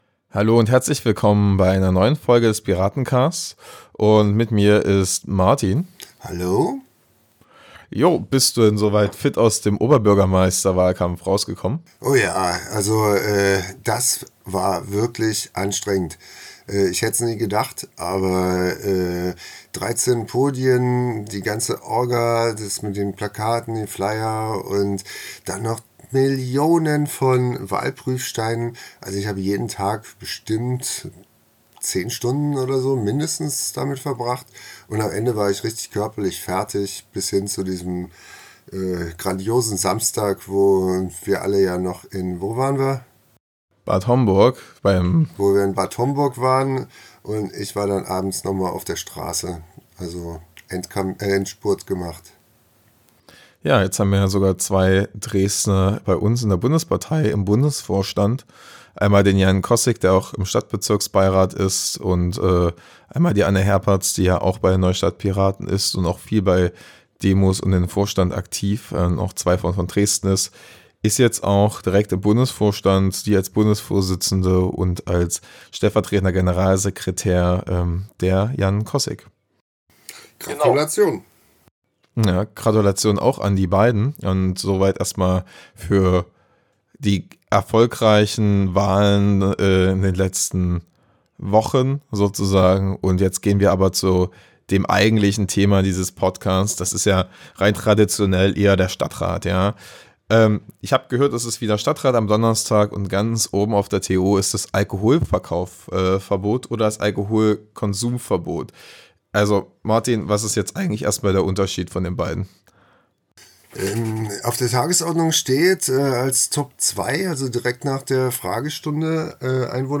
Wir reden heute mit unserem Stadtrat Martin über die möglichen Auswirkungen des Alkoholverbotes oder Konsumverbotes am Assieck.